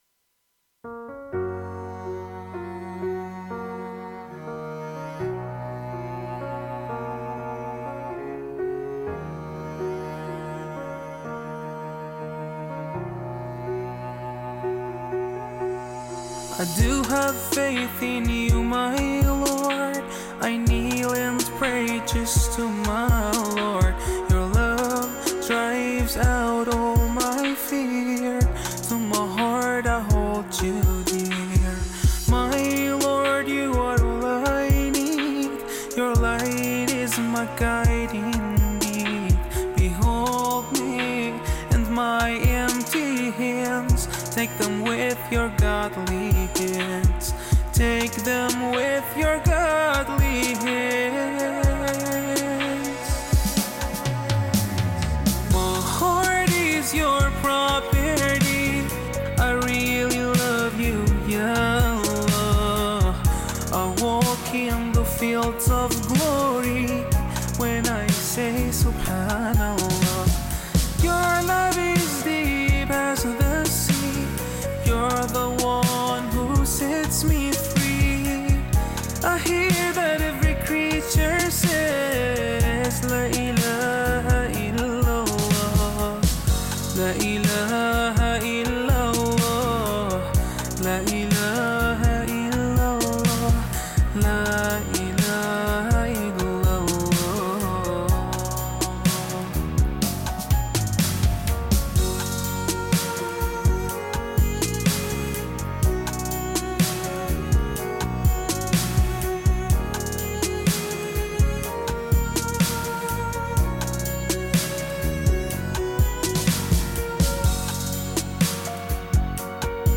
Nashid